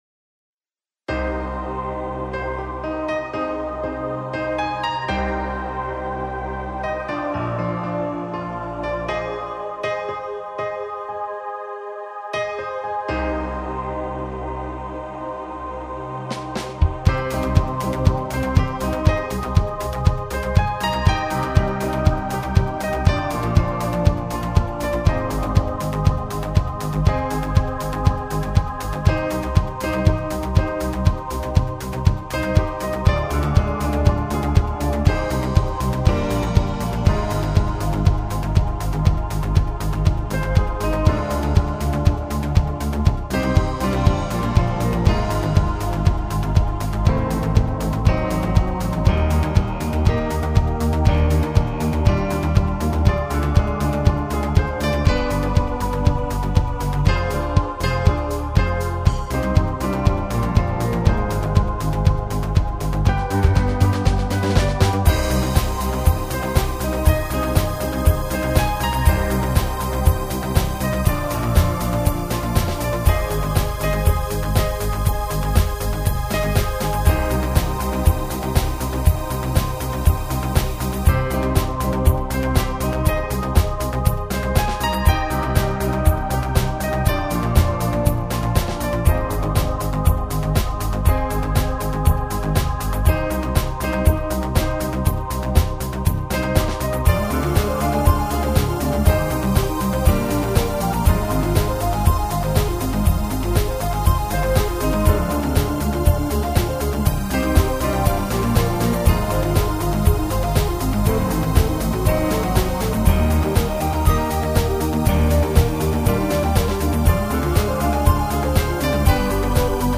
• Жанр: Электронная
инструментальная композиция